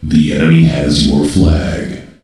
voc_enemy_flag.ogg